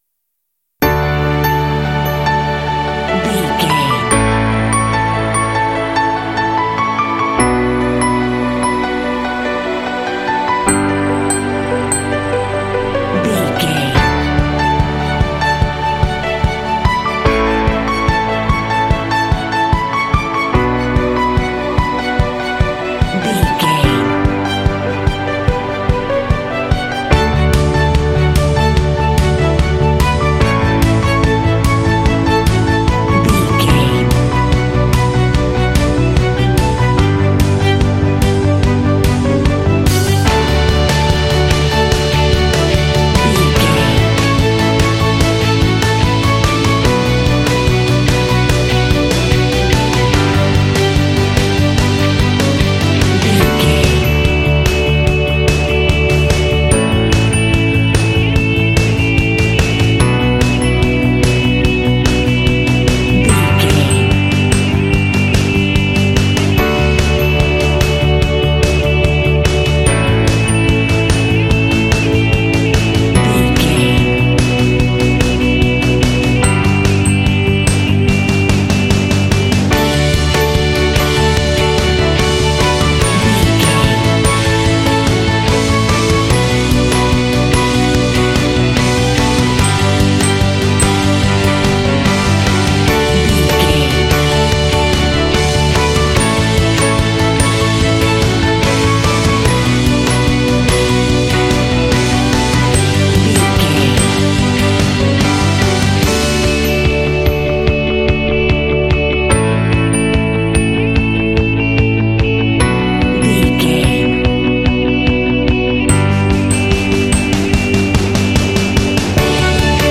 Uplifting
Ionian/Major
D
energetic
bouncy
joyful
cheerful/happy
strings
piano
drums
bass guitar
electric guitar
contemporary underscore
indie